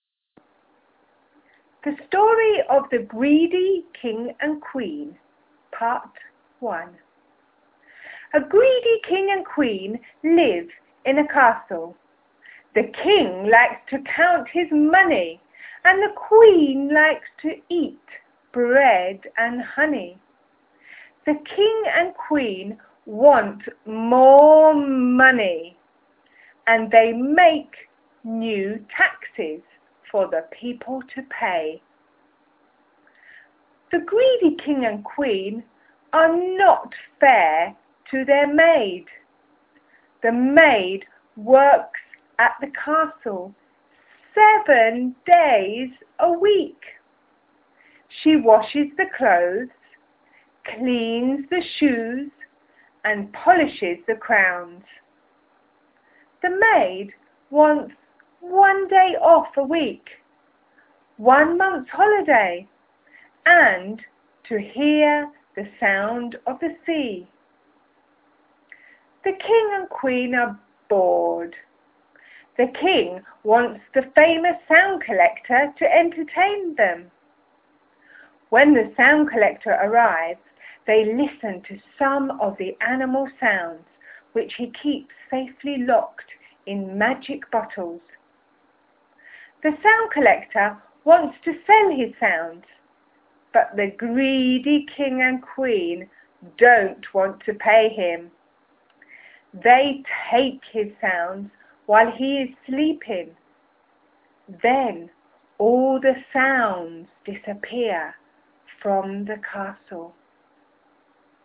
STORY, PART 1